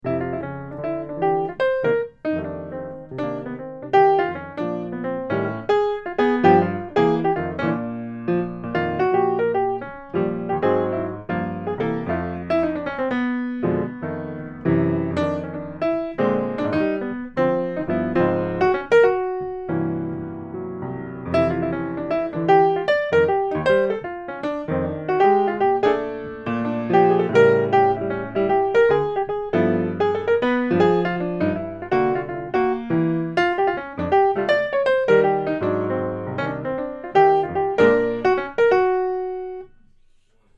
A quirky song with an unusual form